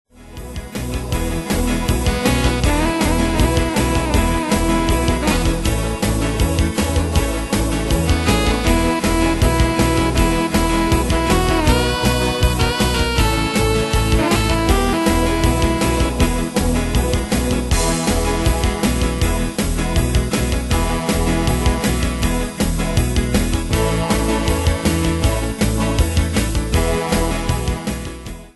Demos Midi Audio
Style: Oldies Année/Year: 1959 Tempo: 159 Durée/Time: 2.39
Danse/Dance: Twist Cat Id.
Pro Backing Tracks